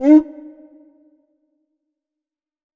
WL CUICA.wav